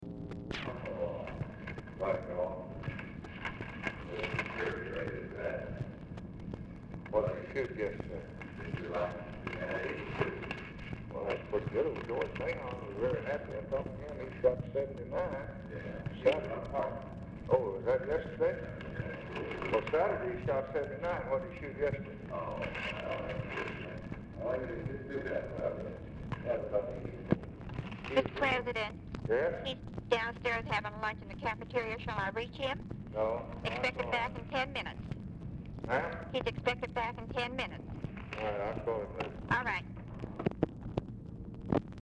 Telephone conversation # 4641, sound recording, LBJ and TELEPHONE OPERATOR, 8/3/1964, time unknown | Discover LBJ
OFFICE CONVERSATION PRECEDES CALL
Format Dictation belt